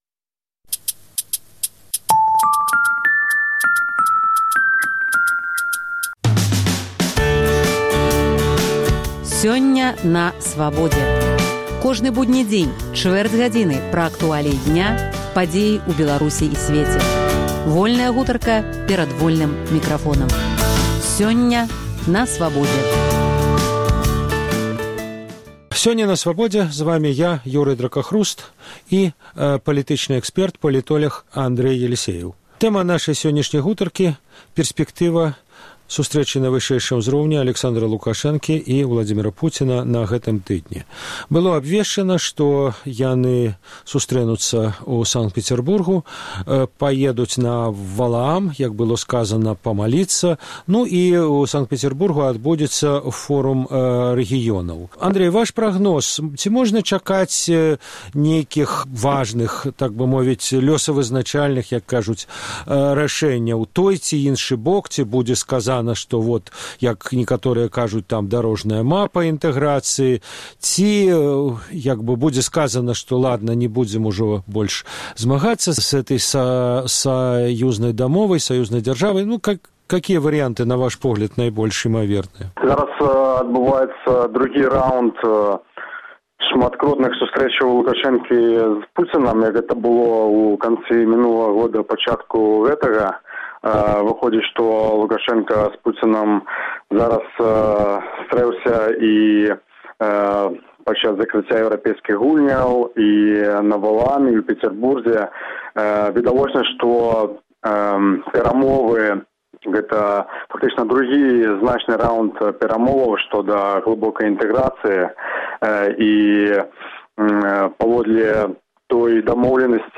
Гутарка пра Усебеларускі з'езд сьнежня 1917 году